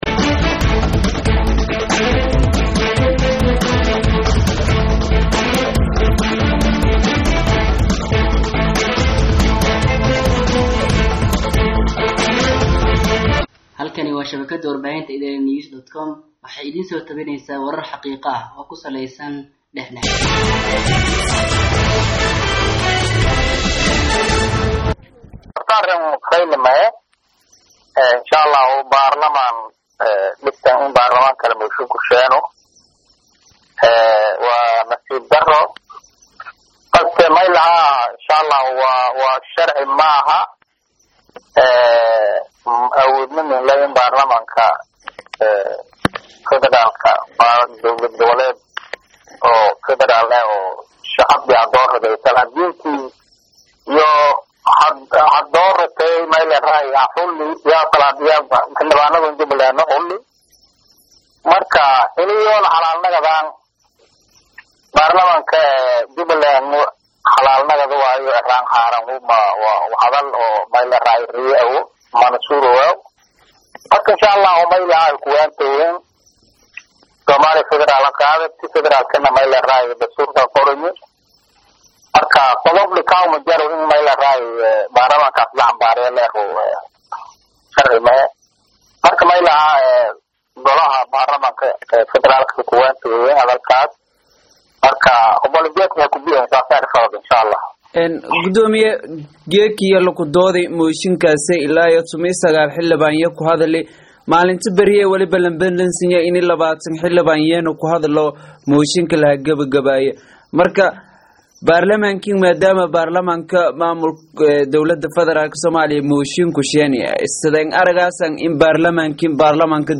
Dhageyso: Wareysi Kuluul Oo Aynu La Yeelaney Gudoomiyaha Baarlamanka Jubba + Hala Kala Saaro KGS iyo Jubba